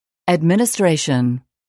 단어번호.0614 대단원 : 3 소단원 : a Chapter : 03a 직업과 사회(Work and Society)-Professions(직업) administration [ædmìnəstréiʃən] 명) 관리, 행정(부) mp3 파일 다운로드 (플레이어바 오른쪽 아이콘( ) 클릭하세요.)